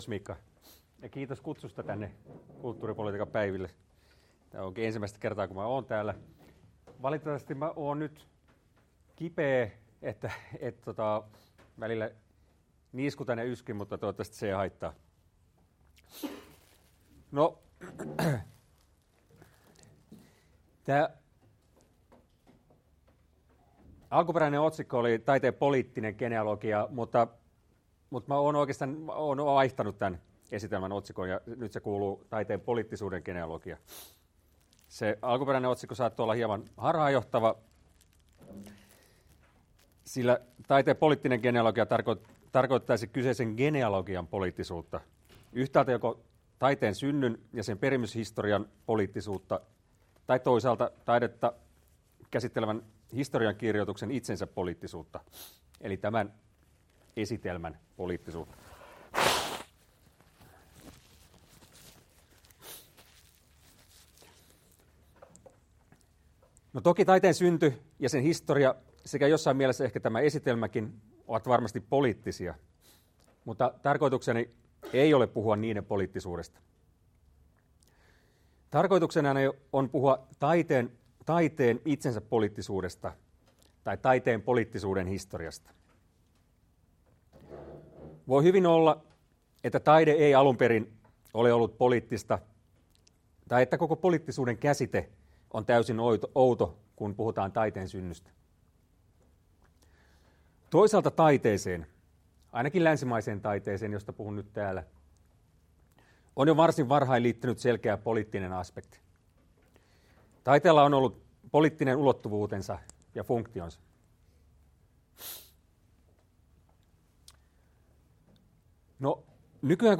Keynote-luento 6.10.2016 — Moniviestin